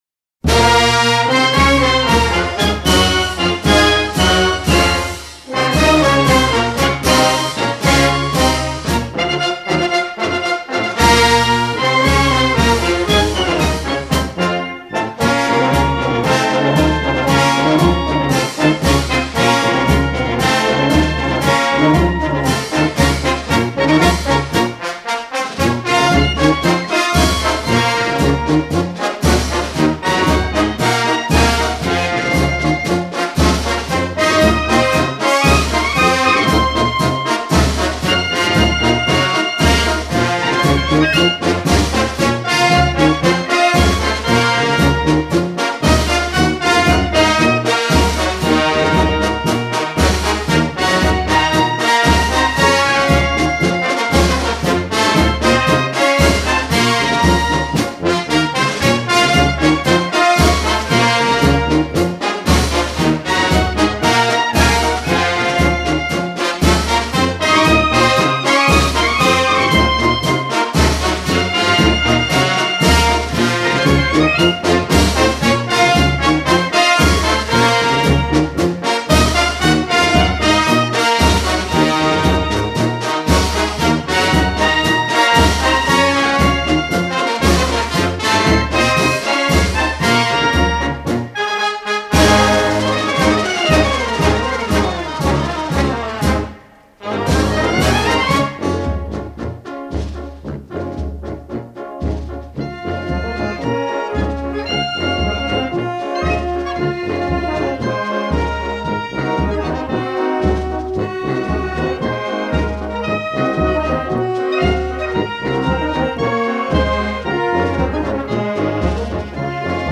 Bandas de música